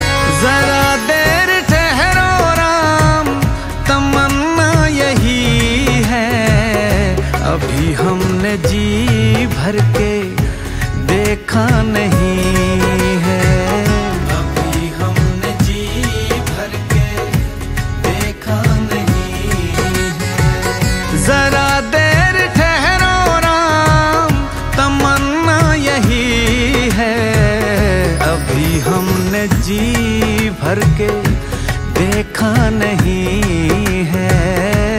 Female Version.